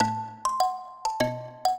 mbira
minuet8-12.wav